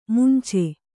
♪ munce